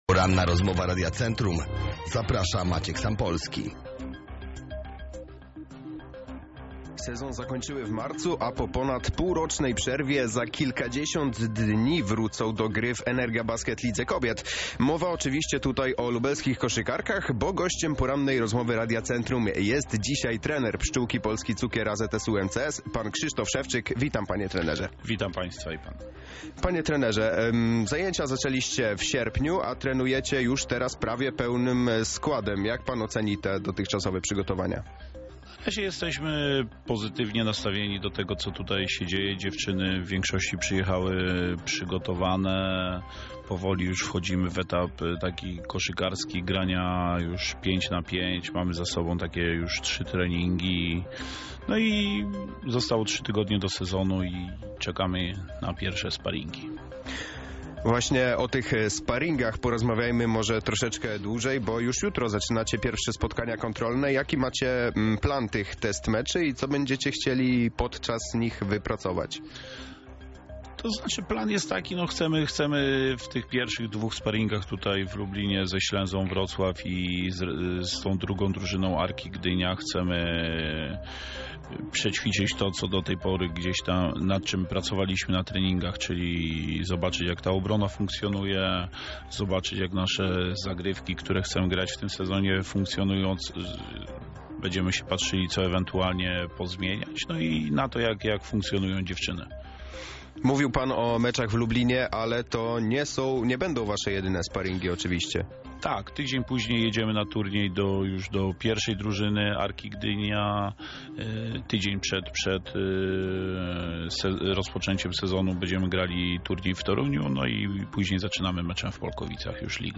Cała rozmowa dostępna jest tutaj: